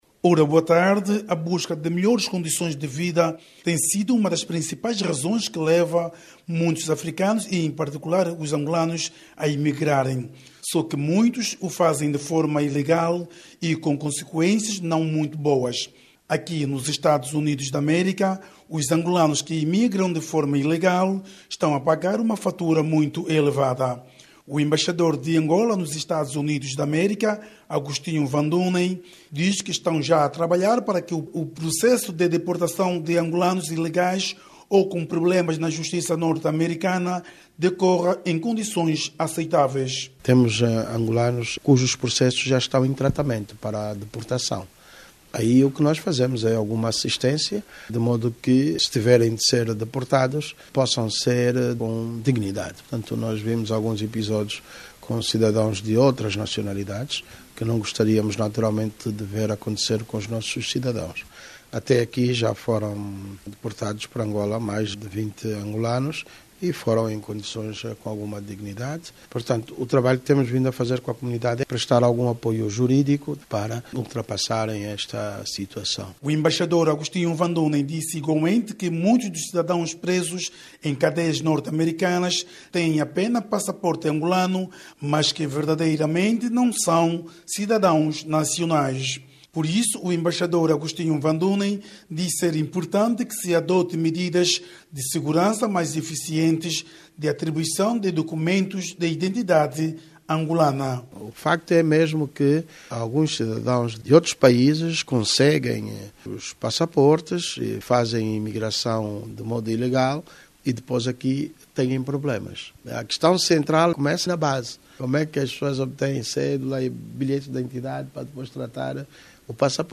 a partir de Washington, EUA